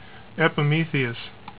"ep eh MEE thee us" ) is the fifth of Saturn's known satellites: